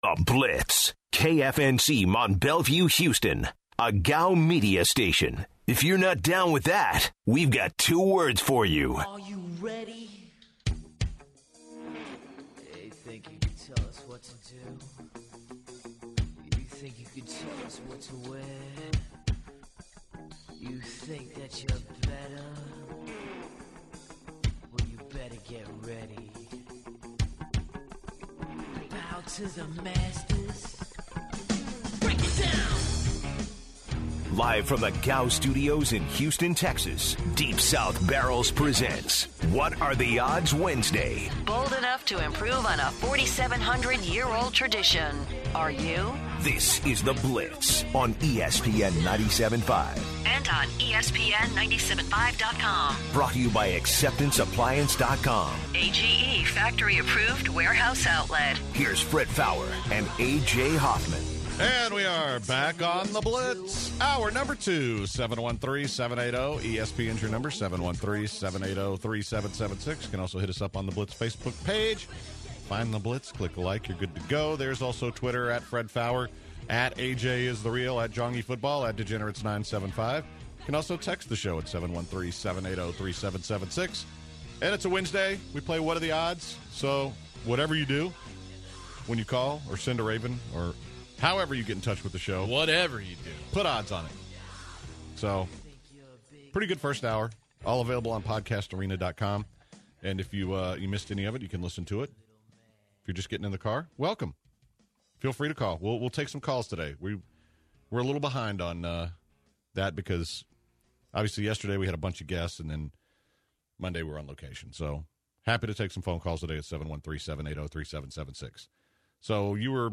In this hour of the show, the guys talk about who can the Rockets trade to help the team. More "what are the odds" questions from callers. Anthony Munoz, NFL hall of fame offensive tackle, joins the show to talk about his plans around Houston to help the community and his thoughts on the Bengals.